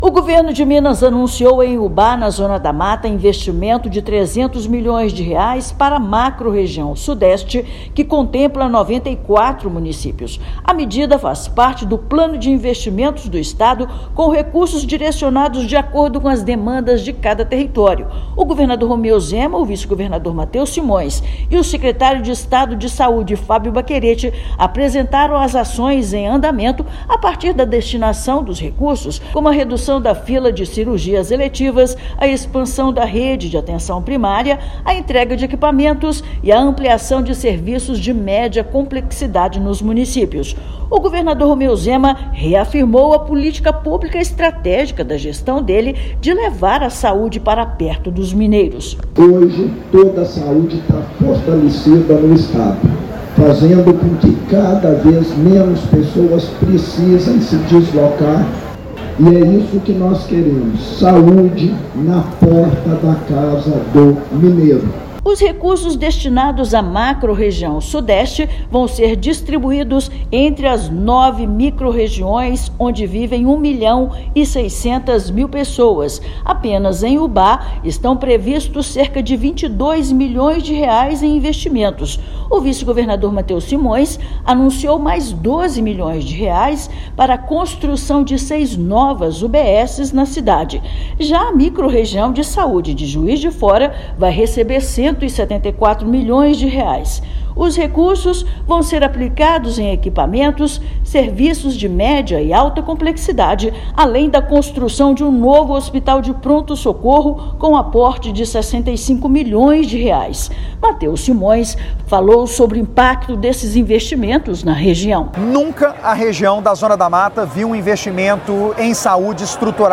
Maior aporte já feito em uma macrorregião vai beneficiar mais de 1,6 milhão de pessoas, descentralizando os serviços para nove territórios, com foco em ampliar acesso, qualidade e resolutividade dos serviços. Ouça matéria de rádio.